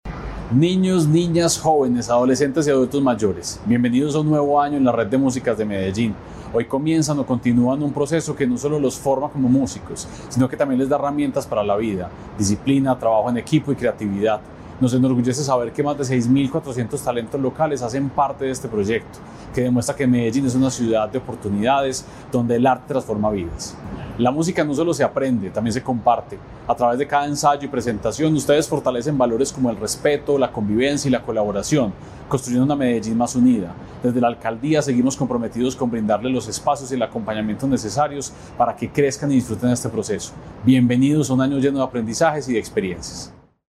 Palabras de Santiago Silva, secretario de Cultura Ciudadana